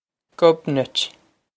Gub-nitch
This is an approximate phonetic pronunciation of the phrase.